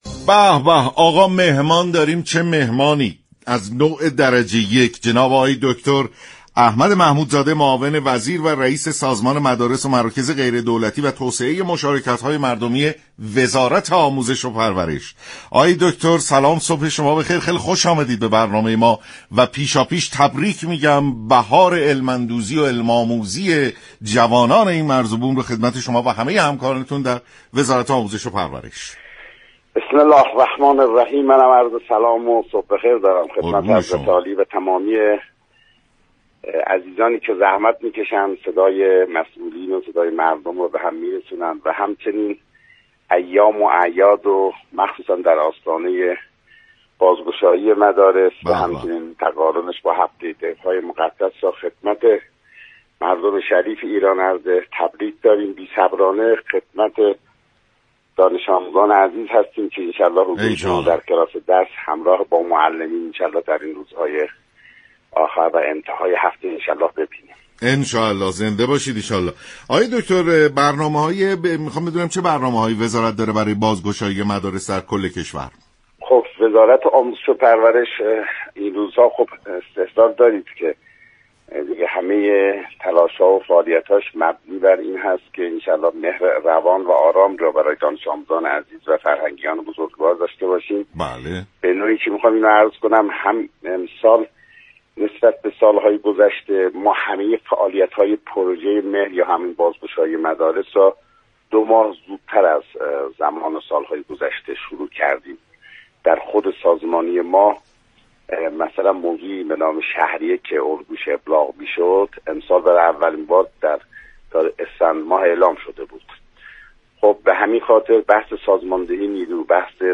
احمد محمودزاده، معاون وزیر و رئیس سازمان مدارس و مراكز دولتی و توسعه مشاركت‌های مردمی وزارت آموزش و پرورش در برنامه سلام‌صبح‌بخیر گفت: دولت می‌خواهد پروژه مهر را طوری پیش ببرد كه حوزه آموزش كمتر دچار كمبود‌ها و نارسایی‌های گذشته شود.